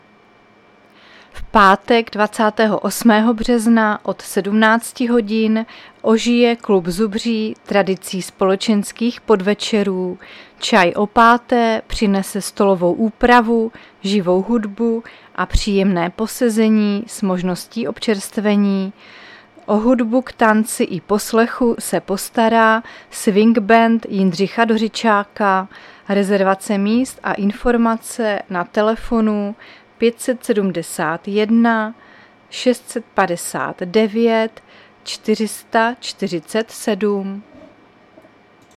Záznam hlášení místního rozhlasu 26.3.2025
Zařazení: Rozhlas